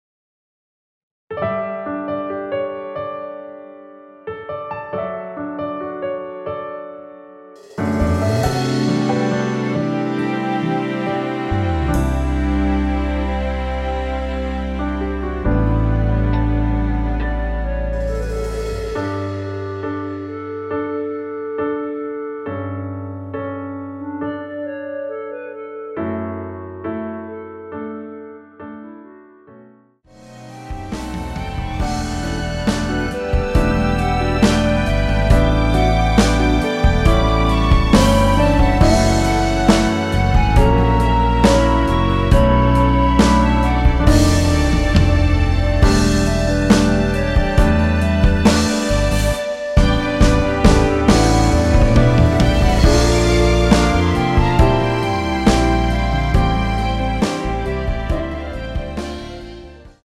원키에서(-1)내린 1절후 후렴으로 진행 되는 멜로디 포함된 MR입니다.(미리듣기 확인)
앞부분30초, 뒷부분30초씩 편집해서 올려 드리고 있습니다.